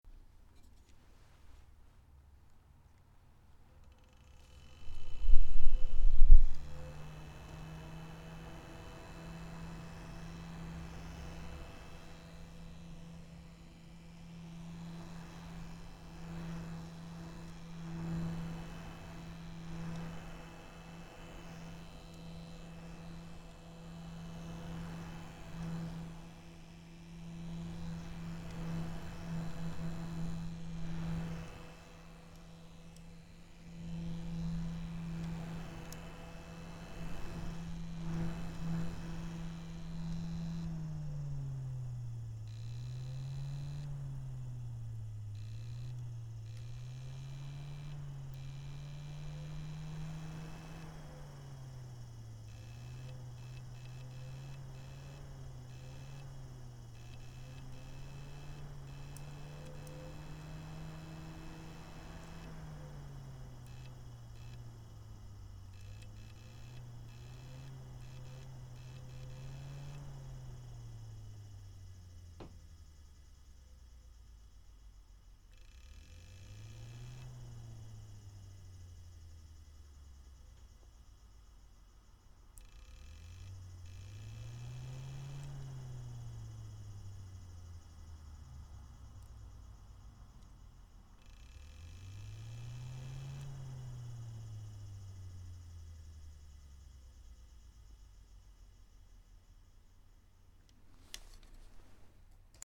Fan-Noise-10.mp3